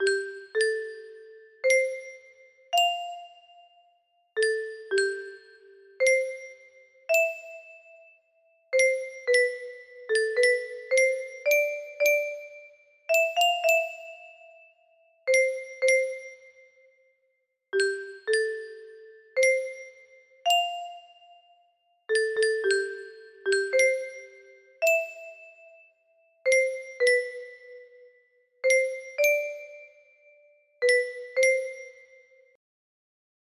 Aloha Oe music box melody